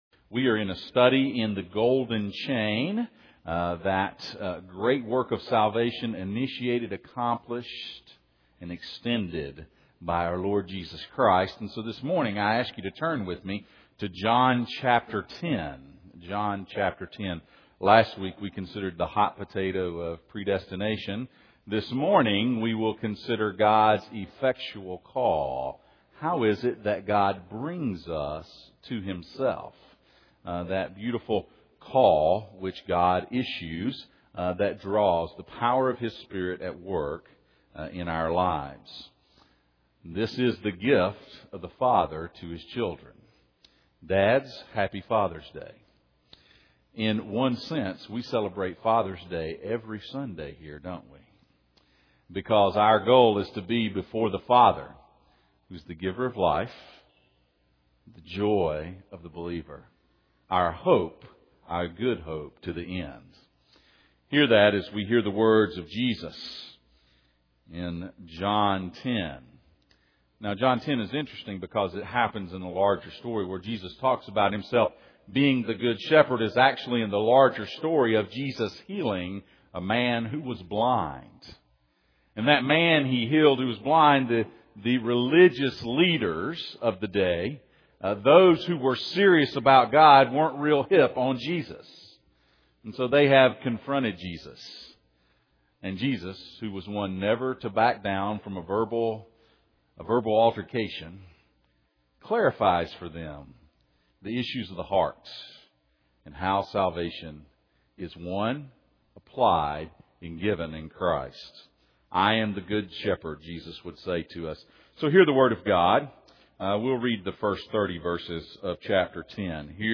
Passage: John 6:37-45 Service Type: Sunday Morning